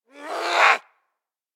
DayZ-Epoch/SQF/dayz_sfx/zombie/chase_2.ogg at e0ad35899e0f8dc0af685a302b1c71695af12710